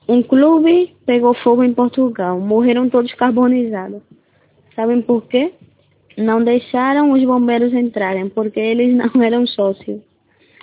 Si? Pois semella que o teu coñecemento do portugués non é nulo...as gravacións son de persoas portuguesas e brasileiras, achas algunha diferenza?